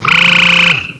pokeemerald / sound / direct_sound_samples / cries / palpitoad.aif